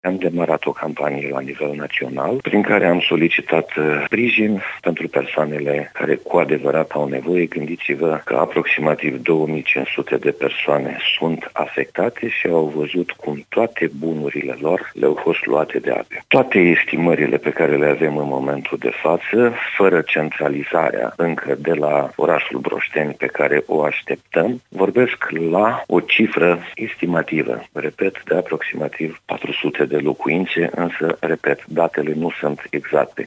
Prefectul de Suceava, Traian Andronachi, a reamintit că a fost lansată o campanie pentru ajutorarea sinistraților afectați de furia apelor, jumătate din populația orașului Broșteni fiind în această situație.